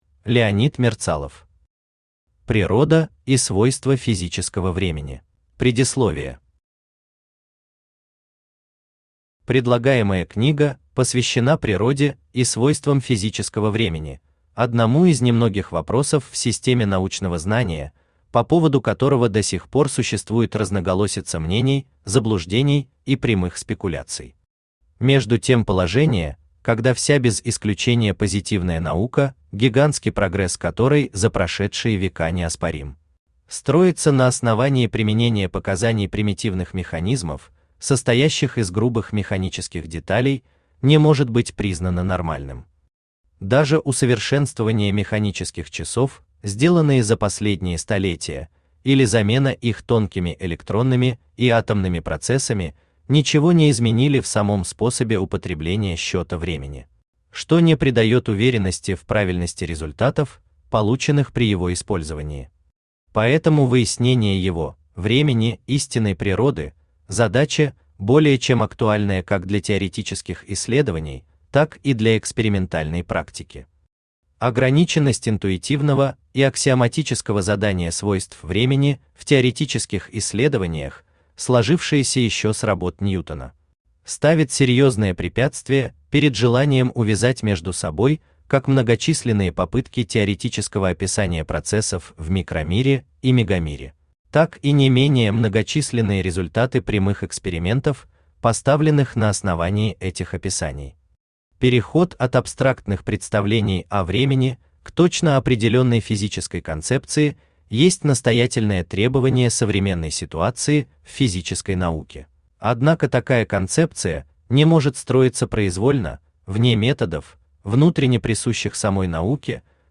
Аудиокнига Природа и свойства физического времени | Библиотека аудиокниг
Aудиокнига Природа и свойства физического времени Автор Леонид Михайлович Мерцалов Читает аудиокнигу Авточтец ЛитРес.